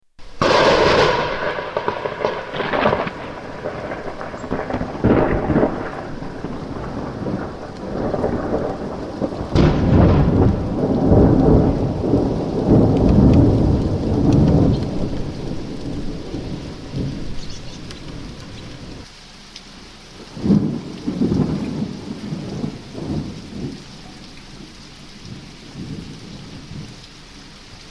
Rainy day in Kornic